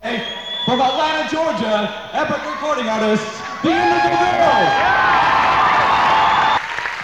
lifeblood: bootlegs: 1989-12-04: the ridge theater - vancouver, british columbia
(acoustic duo show)
01. introduction (0:07)